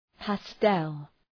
Προφορά
{‘pæstel}